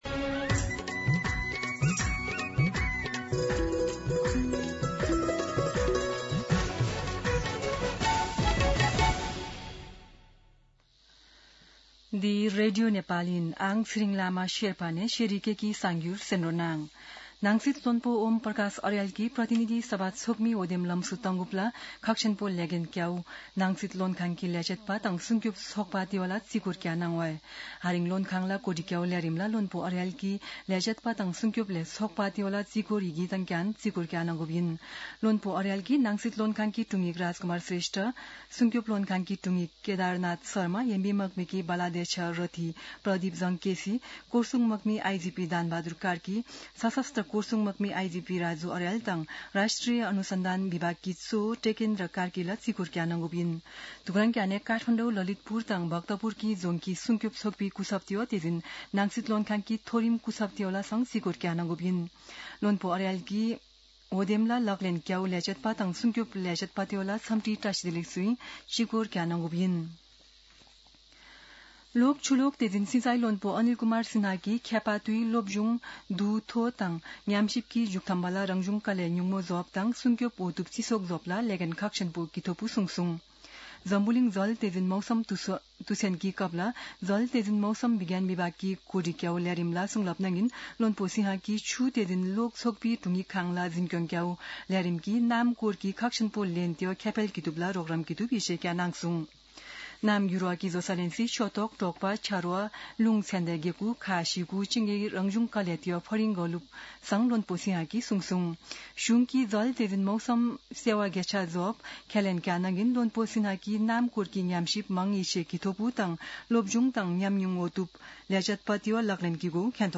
शेर्पा भाषाको समाचार : ९ चैत , २०८२
Sherpa-News-09.mp3